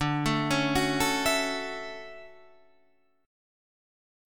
D Major 9th